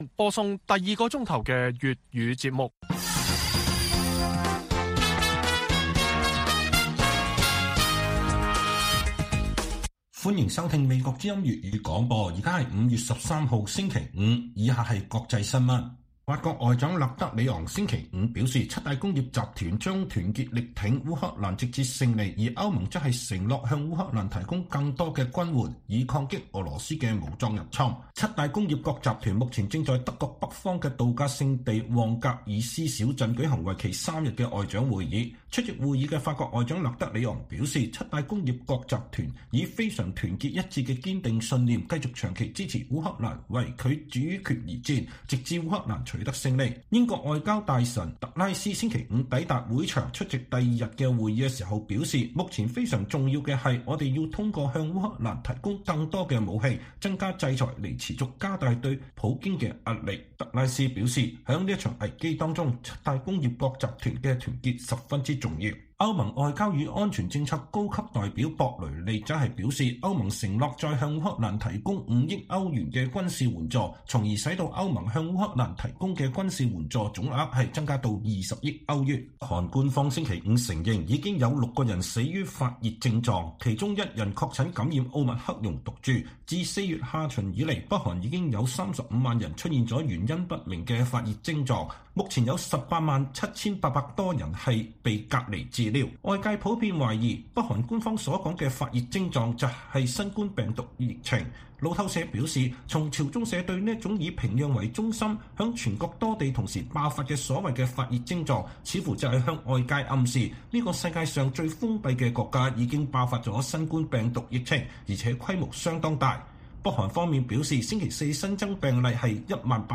粵語新聞 晚上10-11點：歐盟加碼向烏克蘭提供軍援